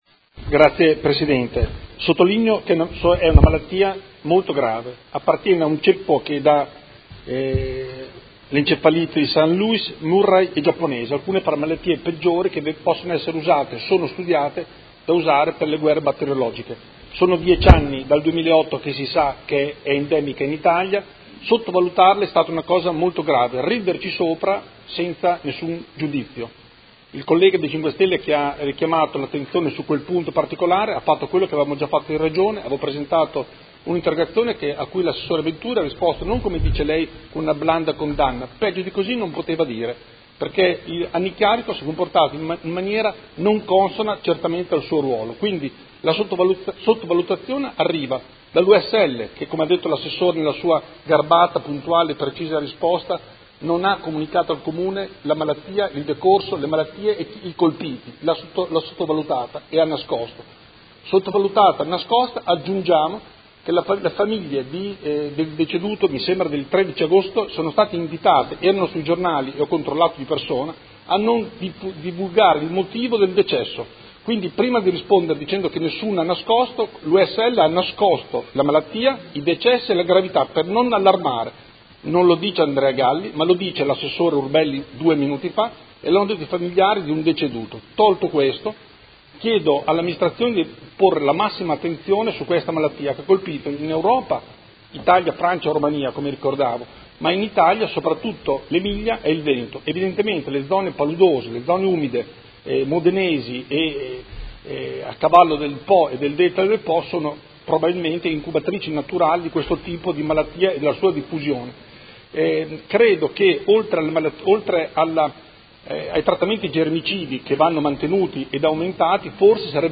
Seduta del 04/10/2018 Replica a risposta Assessora Urbelli. Interrogazione del Consigliere Galli (F.I) avente per oggetto: Diffusione del virus West Nile Virus (WNV) e Interrogazione del Gruppo Consiliare Movimento cinque Stelle avente per oggetto: Virus West Nile.